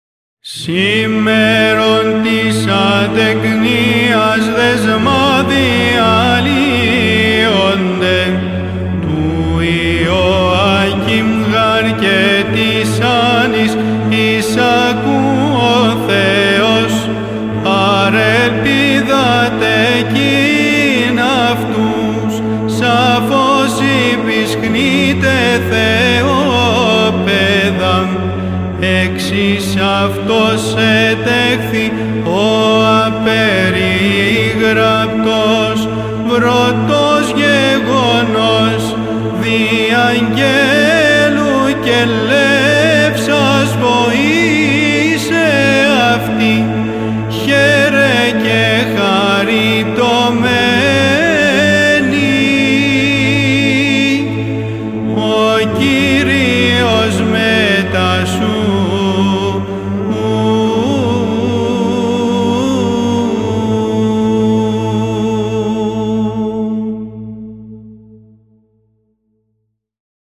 Suplemento Litúrgico
Apolitikion da Festa (Modo 4º)